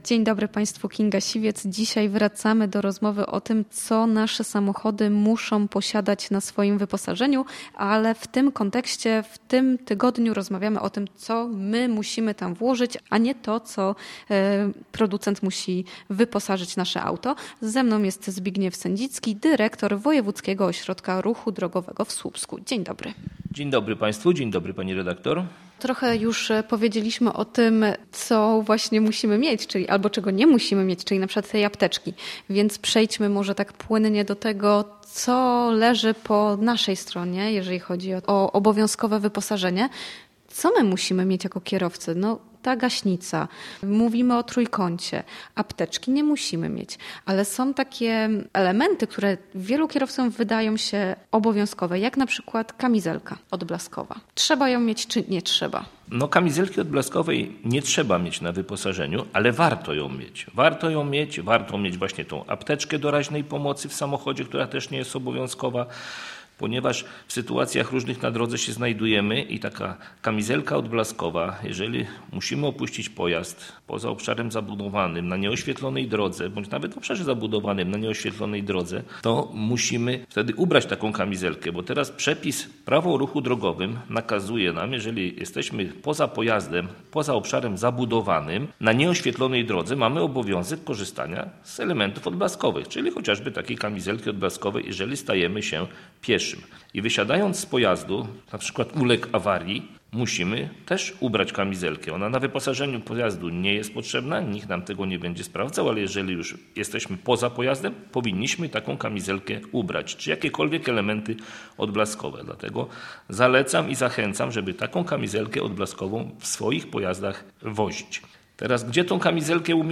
W audycji „Bezpiecznie na drogach” mówiliśmy o tym, w co producent musi wyposażyć auto, a co należy do obowiązku kierowcy. Zapraszamy do wysłuchania rozmowy